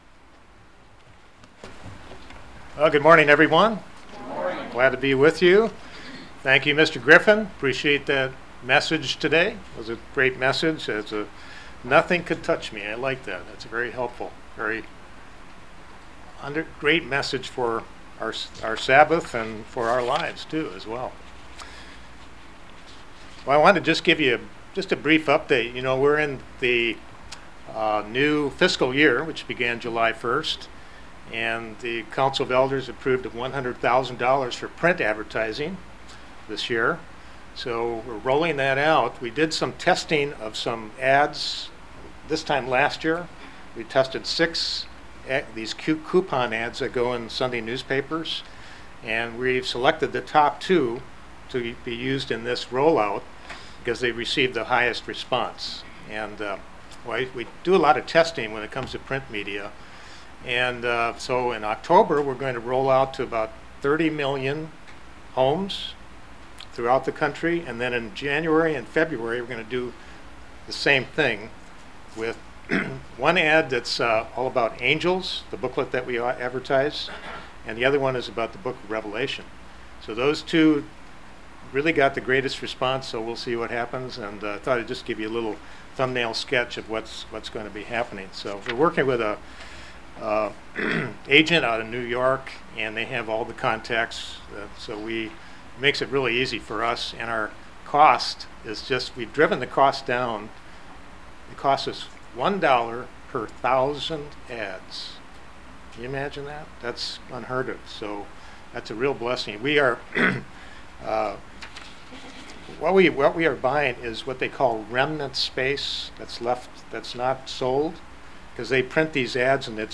This is part 1 of a 2 part sermon. Bible prophecy explains that what will occur in outer space in the time leading up to Jesus Christ’s second coming will directly affect the lives of every person on earth. In this sermon we will cover important facts about the cosmos and examine vital scriptural details to help us better prepare spiritually for what lies ahead.